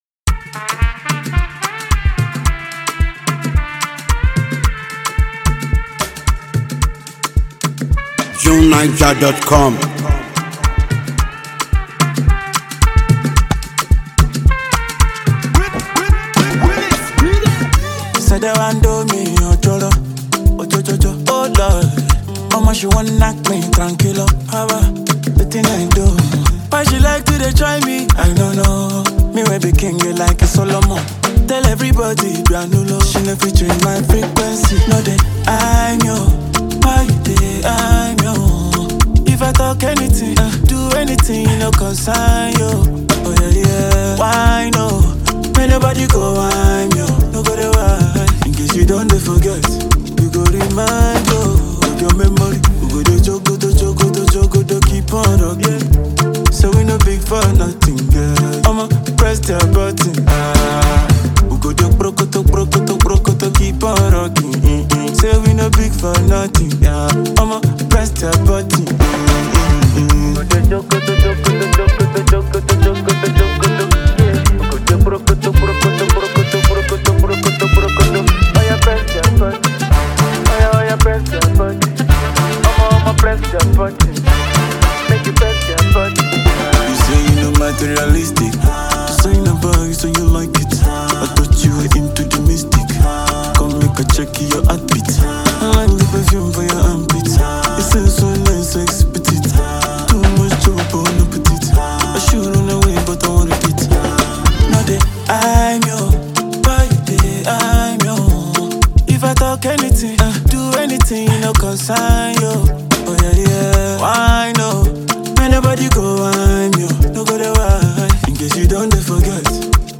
is a feel-good record
Afrobeat